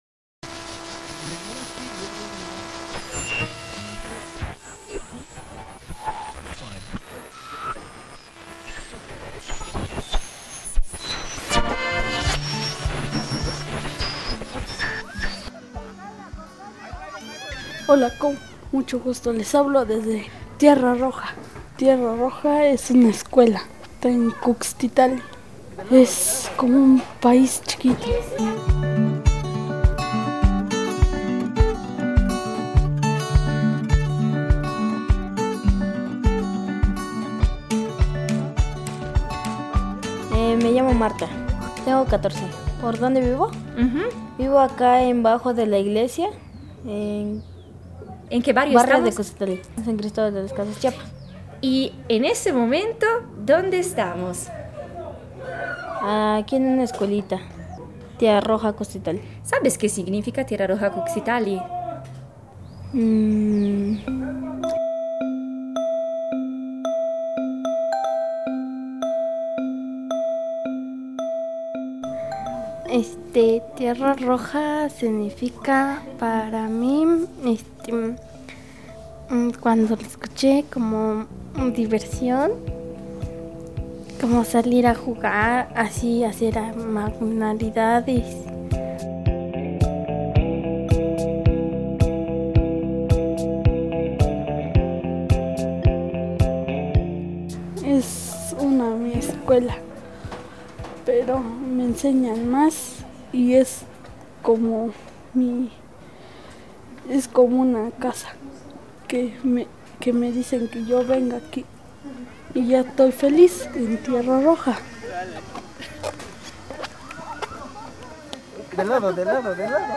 Suoni e parole che raccontano delle storie.
Storie donate ad un registratore e che stanno aspettando di essere raccontate.
Qui un piccolo estratto di quello che vuole essere un progetto audio più ampio, costruito grazie alle voci di chi ogni giorno scrive altre storie possibili.
Sonidos y palabras que cuentan historias.